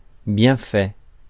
Ääntäminen
IPA: [bjɛ̃.fɛ]